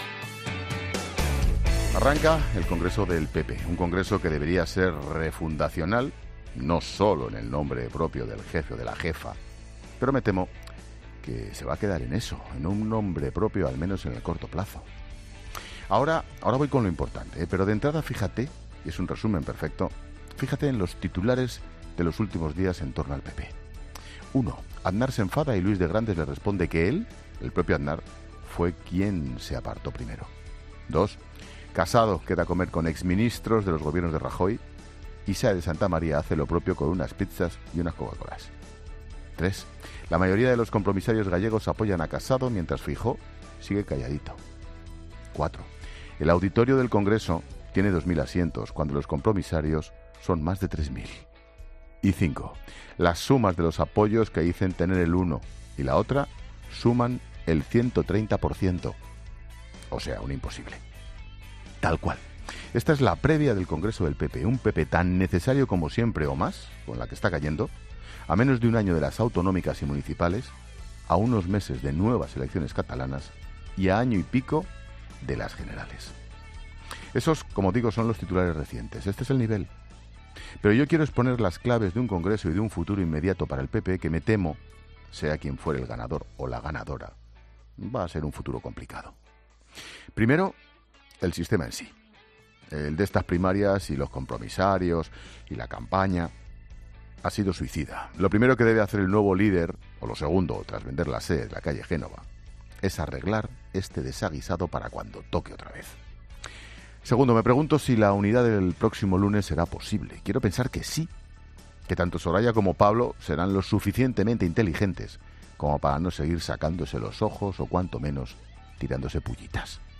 Monólogo de Expósito
El presentador de 'La Tarde', Ángel Expósito, en el monólogo de este viernes ha analizado el Congreso del PP, en el que Sáenz de Santamaría y Casado se juegan el liderato del partido: "Un Congreso que debería ser refundacional... no solo en el nombre propio del jefe o la jefa... y que me temo se va a que dar en eso... en un nombre propio, al menos en el corto plazo".